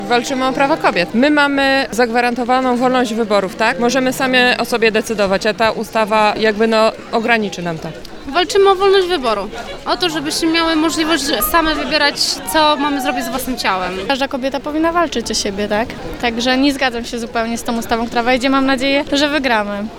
W pikiecie na Rynku Staromiejskim bierze udział kilkaset kobiet.